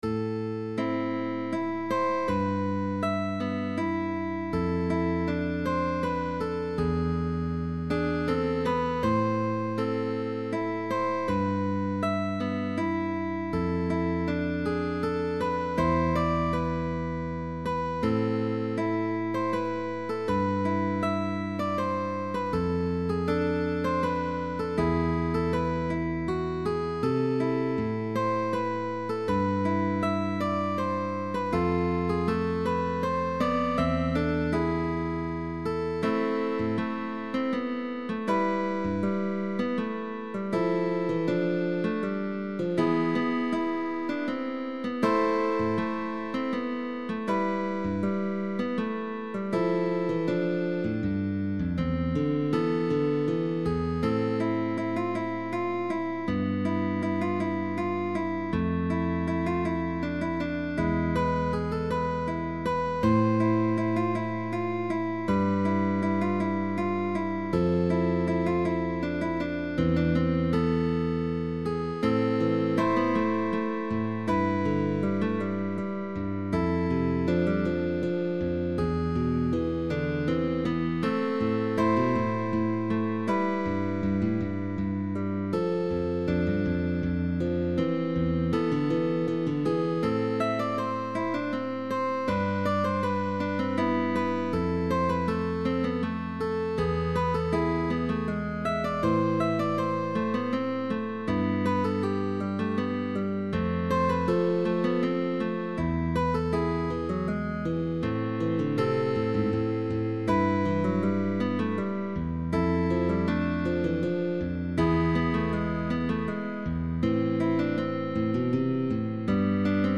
GUITAR DUO
Tag: Baroque